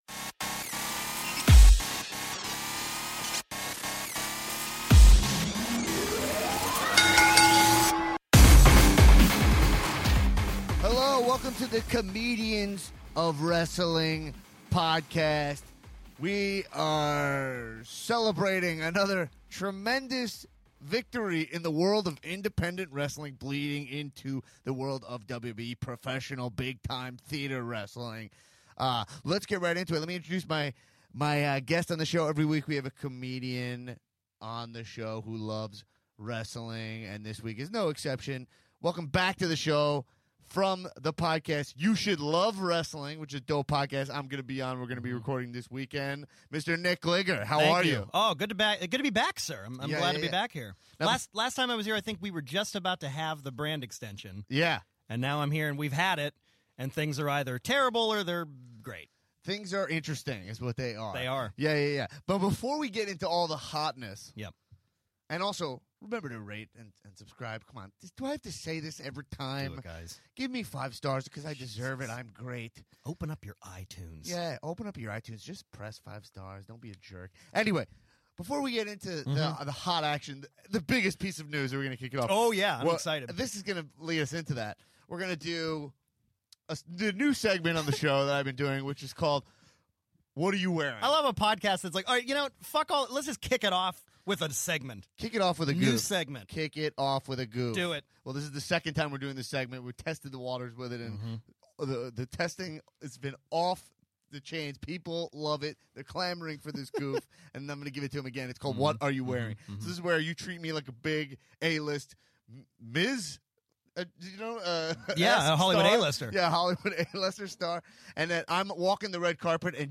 Special guest Vince McMahon stops by the studio to discuss fan’s Smackdown/Raw complaints!